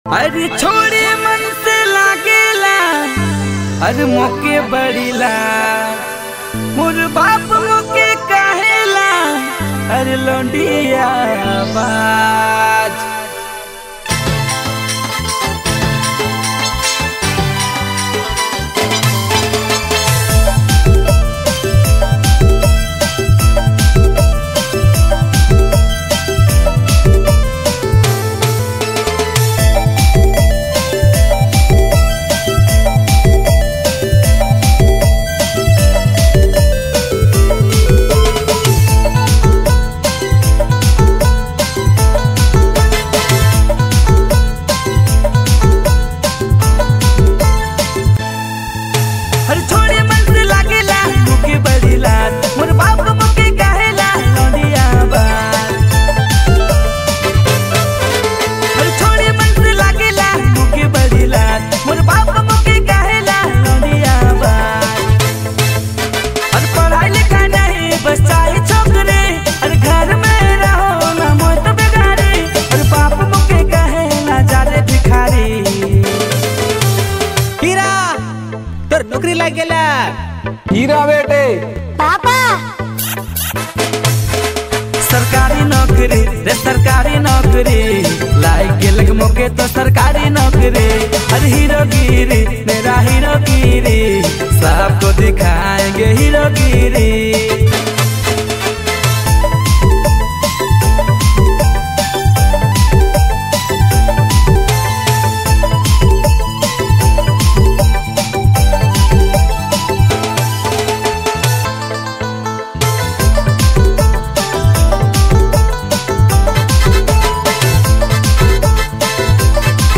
Nagpuri